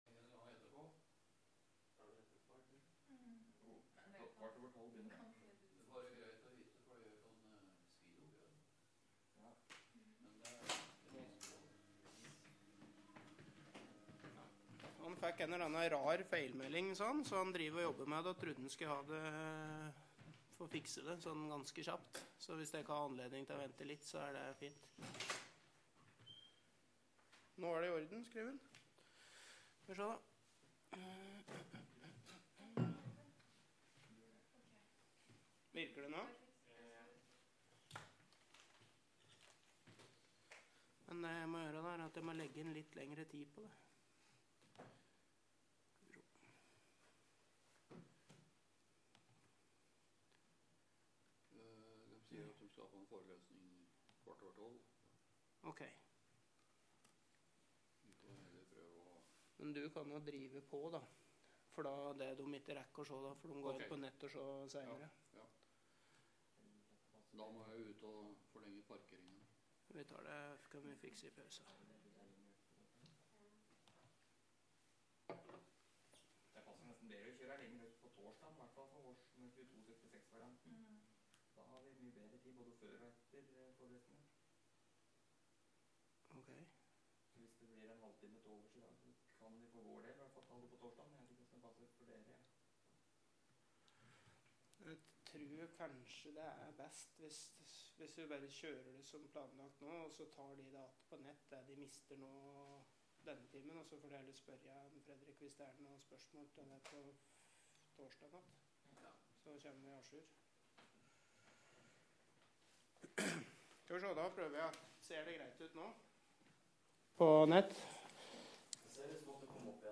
- NTNU Forelesninger på nett